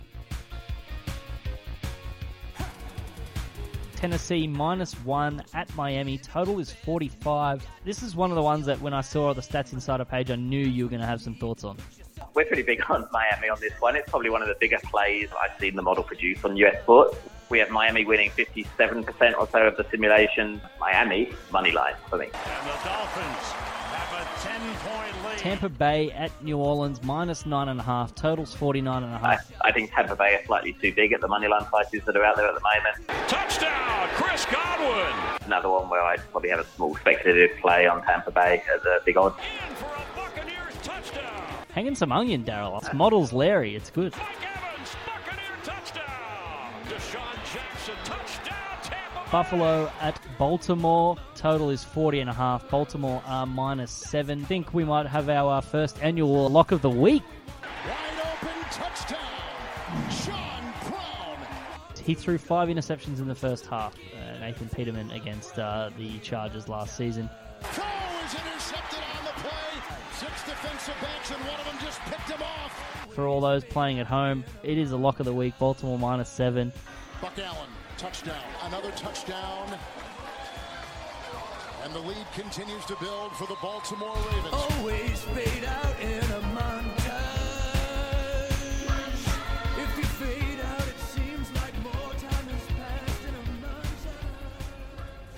UPDATE: We thought we'd keep an eye on results from the Podcast tips so we made a little audio montage for our listeners!